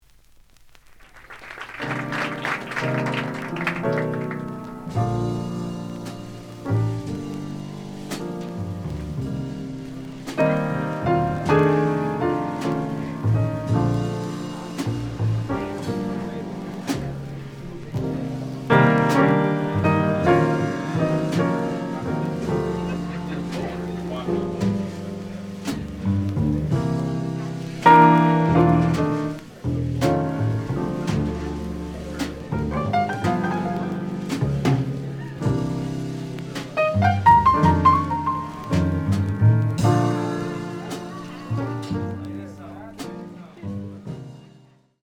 The audio sample is recorded from the actual item.
●Genre: Jazz Funk / Soul Jazz